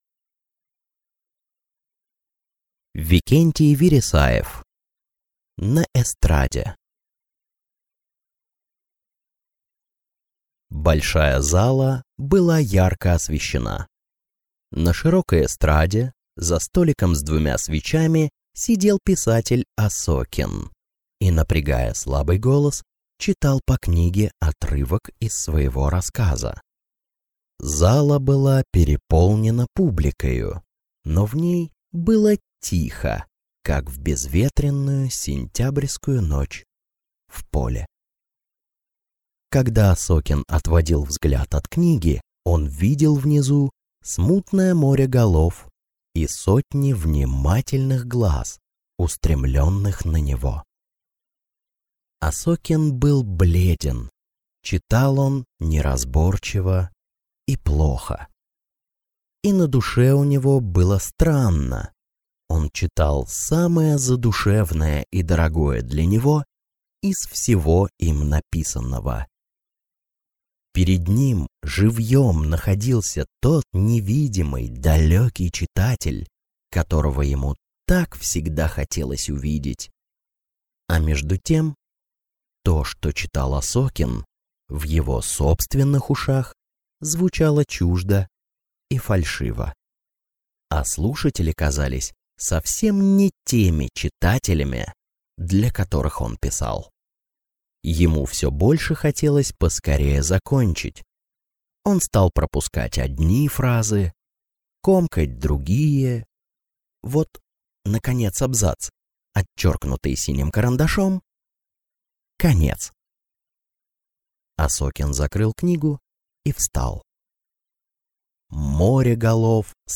Аудиокнига На эстраде | Библиотека аудиокниг